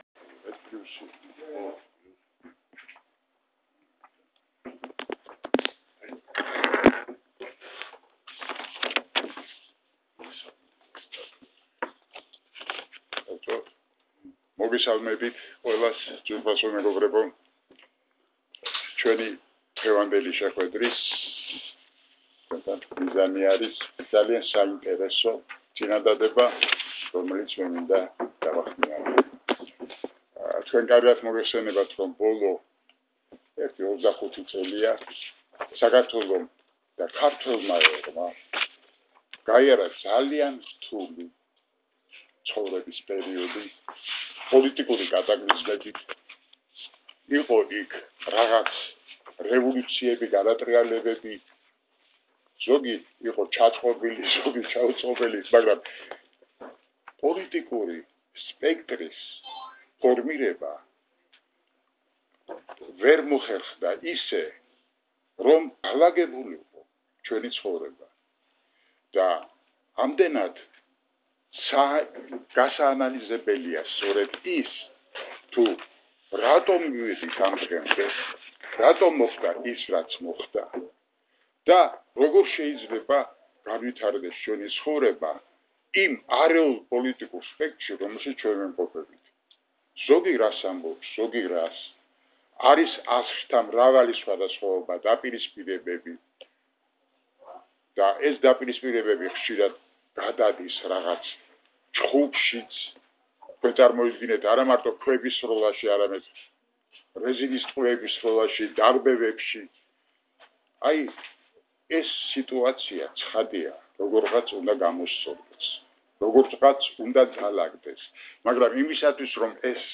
გამოსვლა პრაიმ-თაიმის პრეს-კლუბში (აუდიოჩანაწერი)